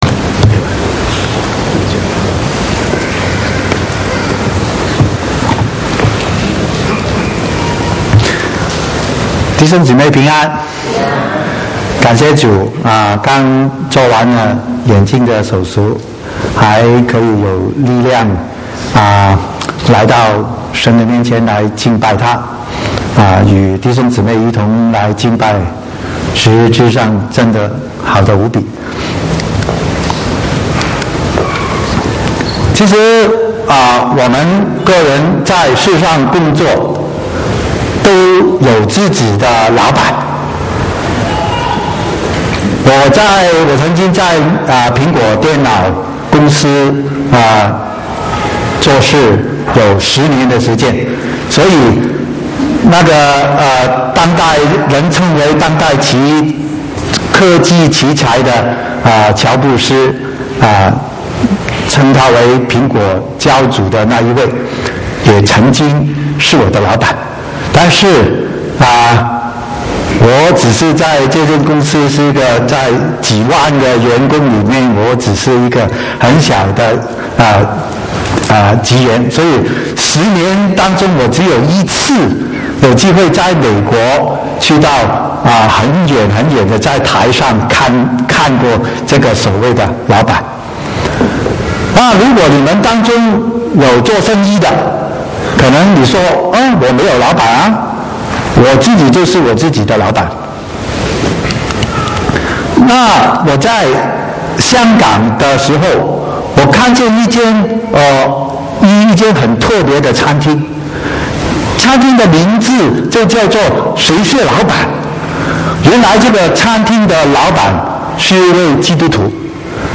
17/7/2016國語堂講道